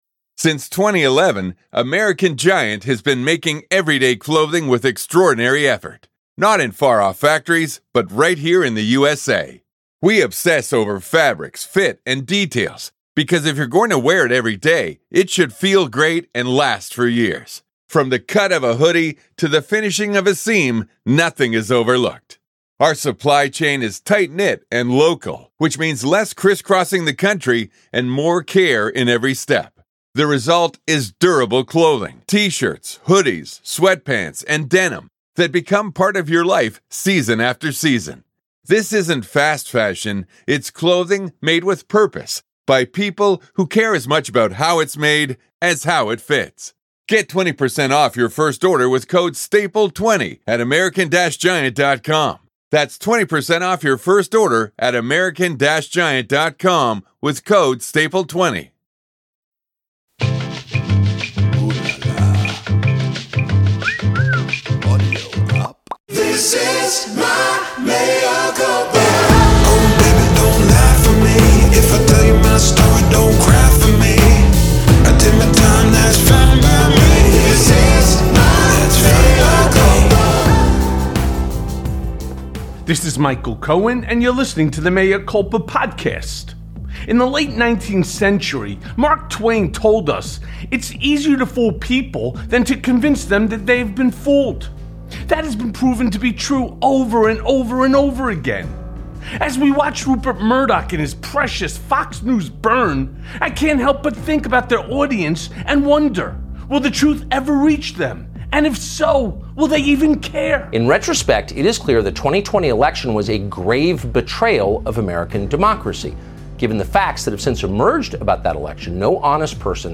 Breaking!!! Criminal Charges For Trump Likely + A Conversation with Glenn Kirschner